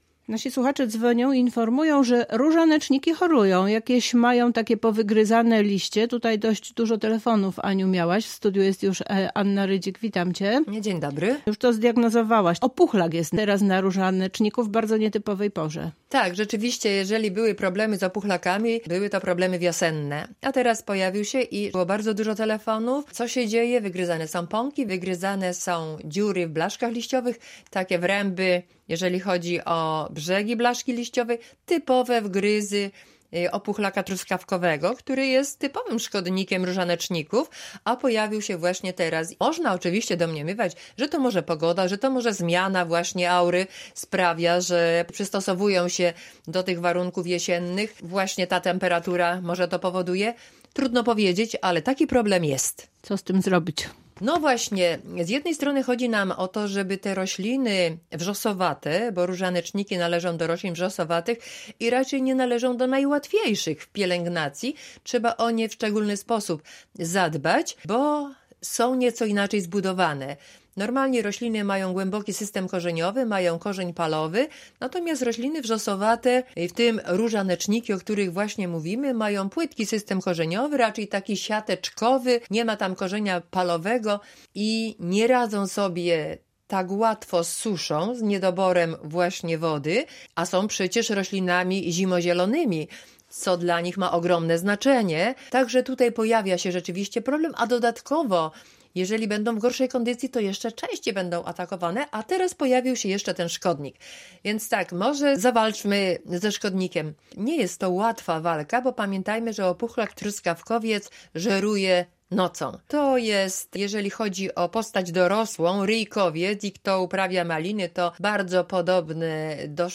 Jak to zrobić, by rośliny osłabione przez szkodnika dobrze przetrwały trudny czas zimowy? Odpowiedź na to pytanie w rozmowie